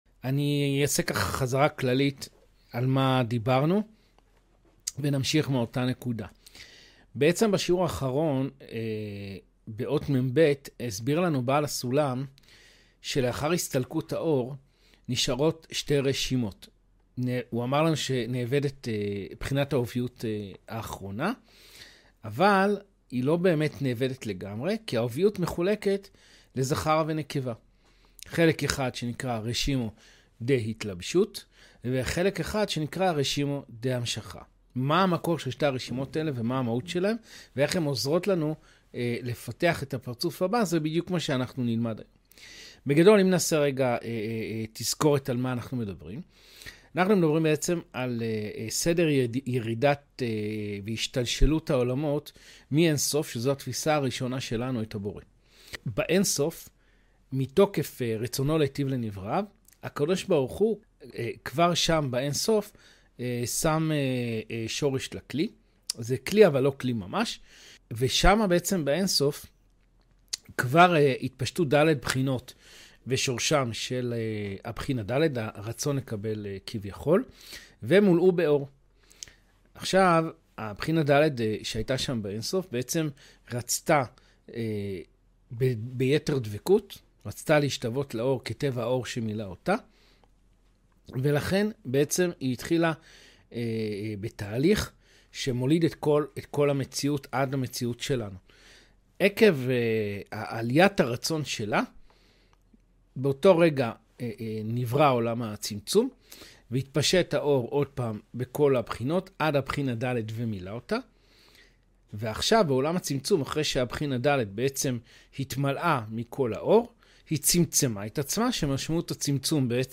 מהו סדר השתלשלות העולמות הרוחניים עד יציאת הרשימו? שעור קבלה